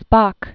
(späk), Paul Henri Charles 1889-1972.